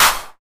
Clap SwaggedOut 4.wav